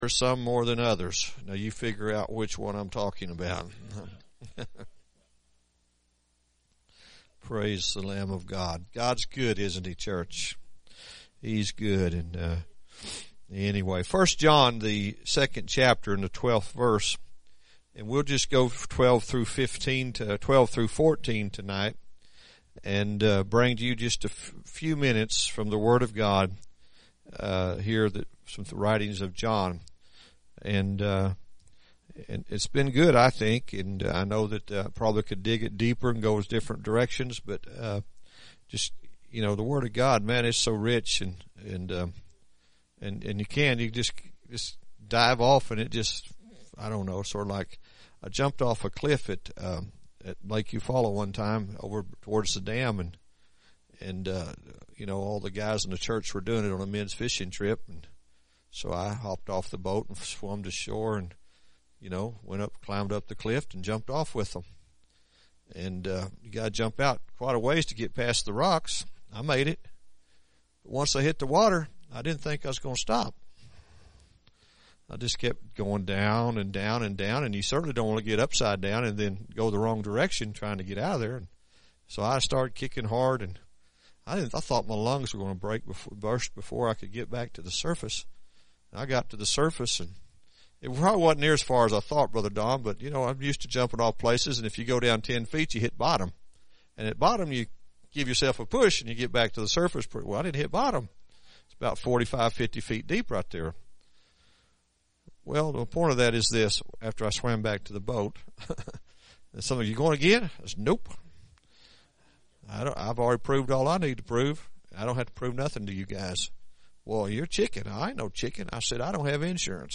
1 John Series – Sermon 4